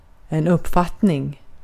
Ääntäminen
Ääntäminen US Haettu sana löytyi näillä lähdekielillä: englanti Käännös Ääninäyte Substantiivit 1. uppfattning {en} Määritelmät Substantiivit A general thought, feeling , or sense.